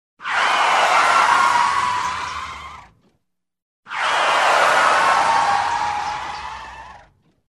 Звуки тормоза автомобиля - скачать и слушать онлайн бесплатно в mp3